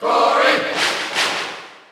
Crowd cheers (SSBU) You cannot overwrite this file.
Corrin_Cheer_Dutch_SSBU.ogg